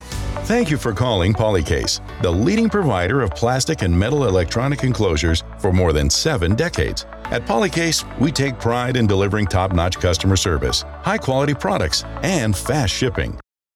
Englisch (Amerikanisch)
Kommerziell, Unverwechselbar, Vielseitig, Warm, Corporate
Telefonie